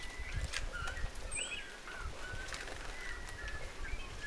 punatulkun huikahdusääni, 2
toisenlainen_punatulkun_huikahdus.mp3